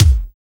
STOMP KICK.wav